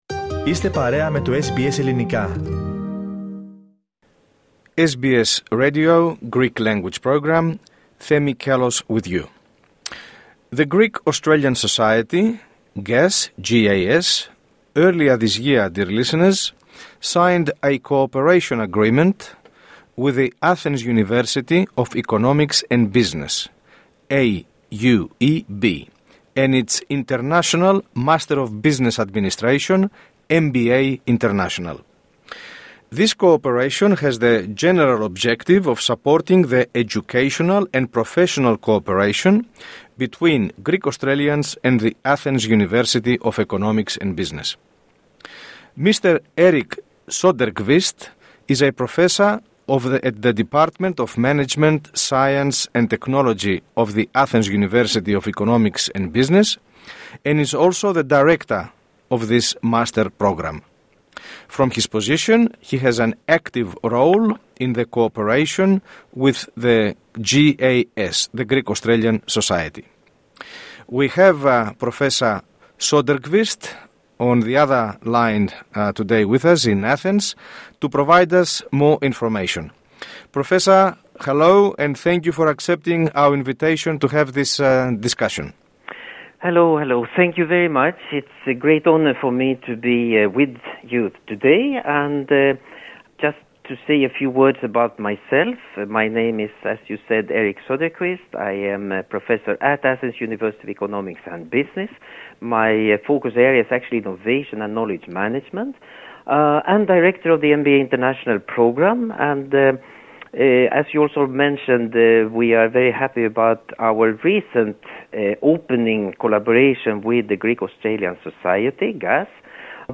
Two version of the interview are provided in this article, English and Greek.